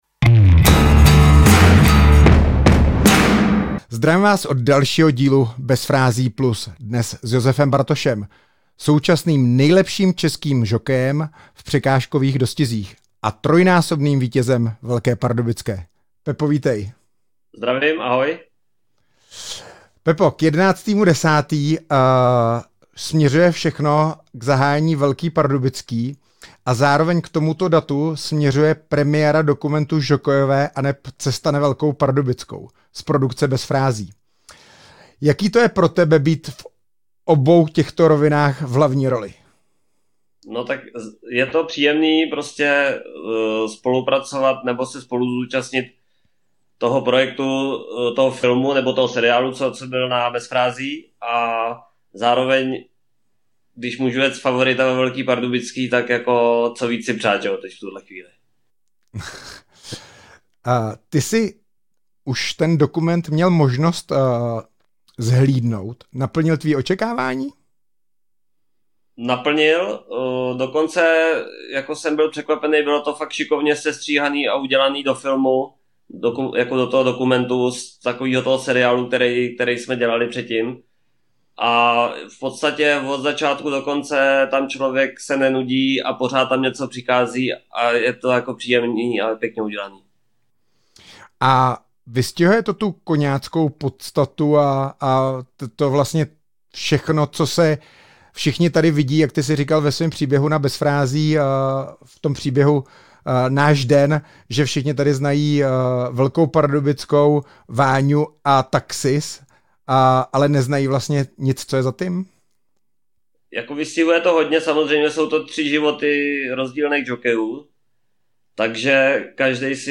Dalším hostem v pořadu Bez frází+ byl Josef Bartoš.
Rozpovídal se o Velké pardubické, o legendárním Taxisově příkopu, jeho sportovních snech i novém dokumentárním filmu z naší produkce, který mapuje roční cestu tří žokejů až na start samotné Velké pardubické. Rozhovor jsme natočili jen pár dní před Josefovým zraněním, které ho z letošního ročníku vyřadilo.